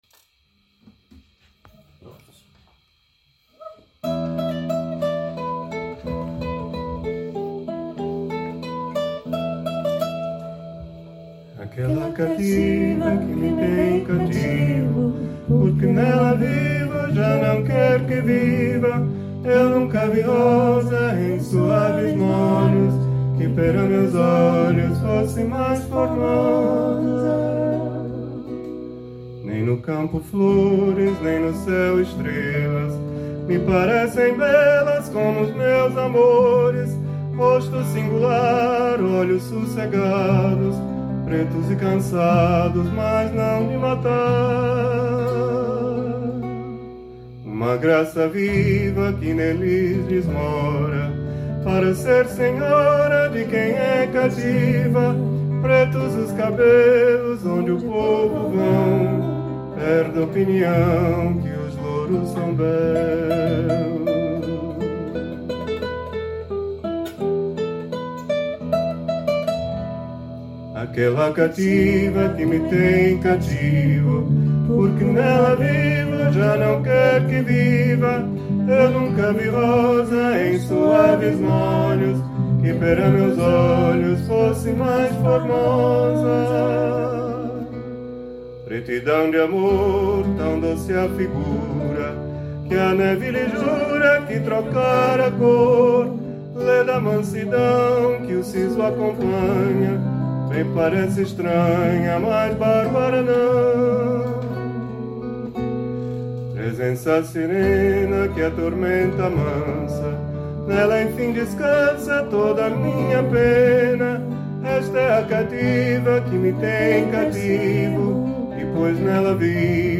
Hier befindet sich eine Auswahl unseres Repertoires mit Sheets und einigen Audio-Aufnahmen der Stücke, die in den Proben gemacht wurden.